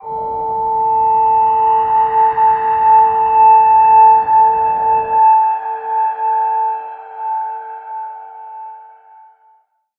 G_Crystal-A5-pp.wav